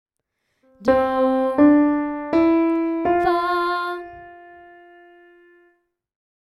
Let’s sing the major scale and emphasize the fourth:
Hearing-Harmonies-1-Ex-6-Major-key-with-tonic-and-subdominant-emphasised.mp3